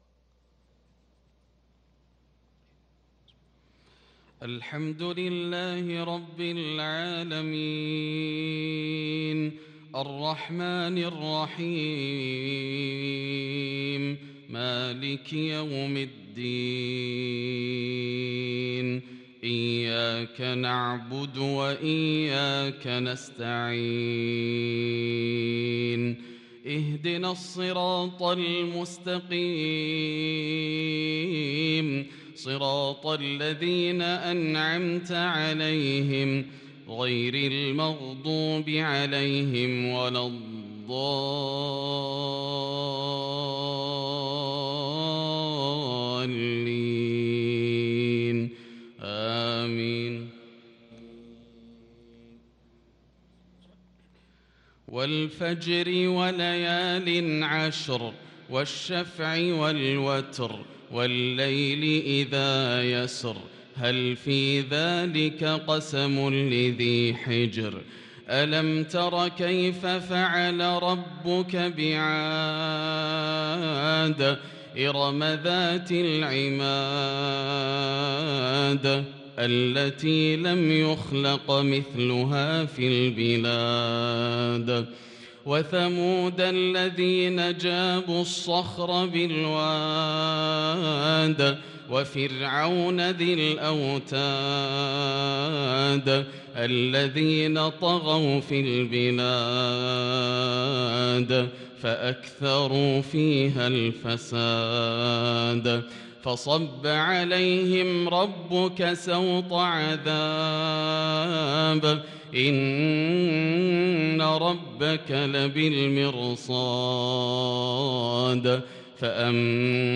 صلاة العشاء للقارئ ياسر الدوسري 5 جمادي الأول 1443 هـ
تِلَاوَات الْحَرَمَيْن .